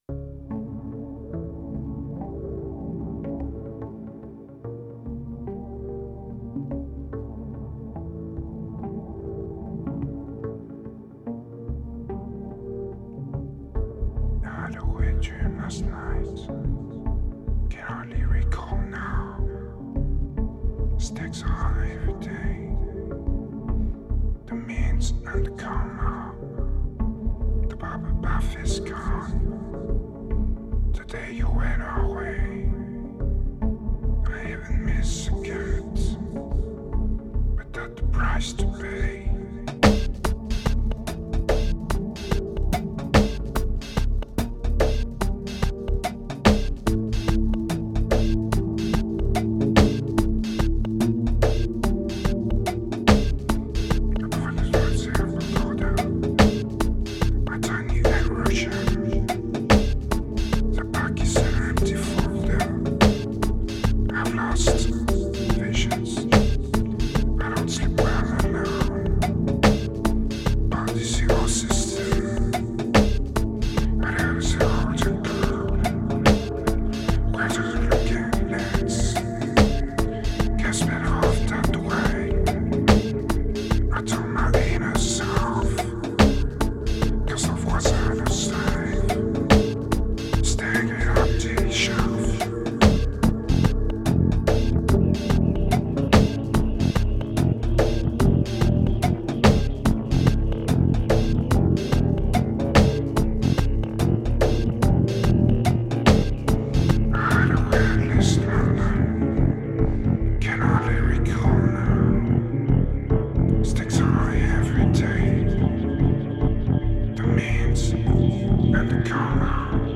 Pure remote desire, strong medication and raw electronica.